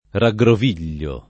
vai all'elenco alfabetico delle voci ingrandisci il carattere 100% rimpicciolisci il carattere stampa invia tramite posta elettronica codividi su Facebook raggrovigliare v.; raggroviglio [ ra gg rov & l’l’o ], -gli